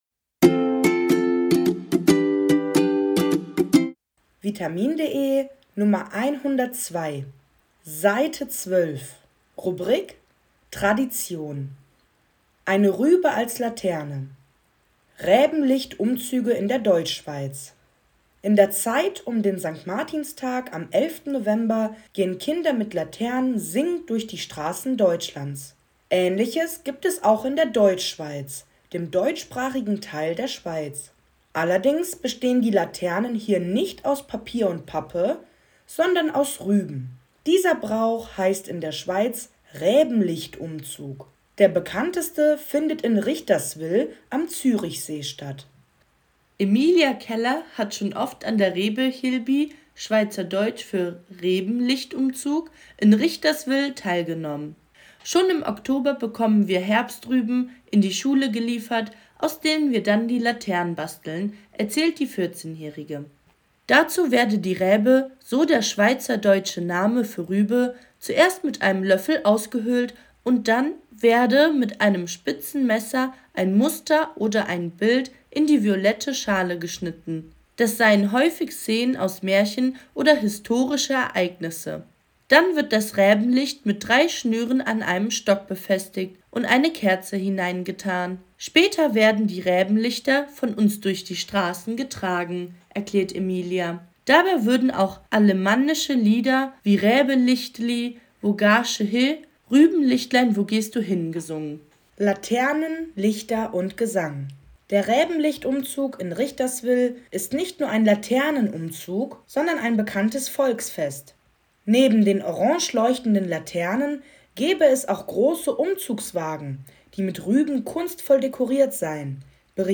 Audiodatei (Hörversion) zum Text
Intro-Melodie der Audios